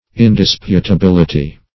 Indisputability \In*dis`pu*ta*bil"i*ty\, n. [Cf. F.